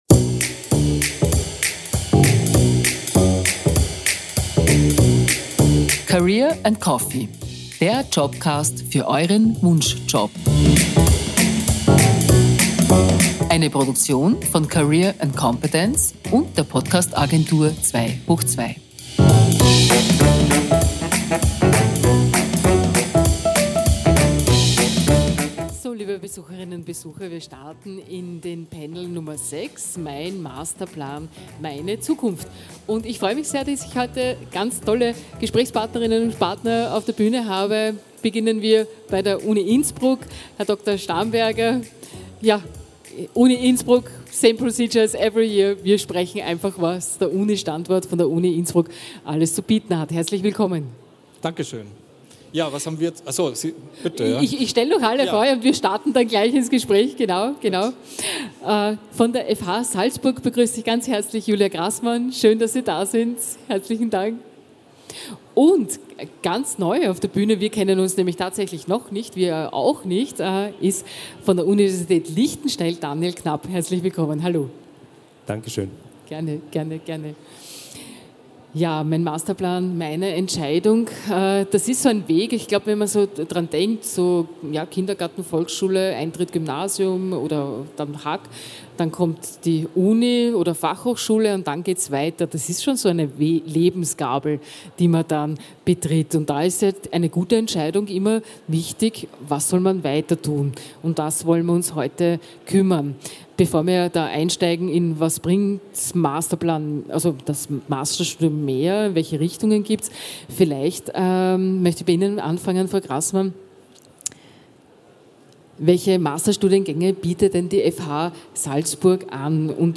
Genau darum geht es in dieser Bühnendiskussion. Repräsentanten der Universität Innsbruck, FH Salzburg und Universität Liechtenstein beleuchten verschiedene Aspekte & Gründe für die Wahl des Masterstudienganges.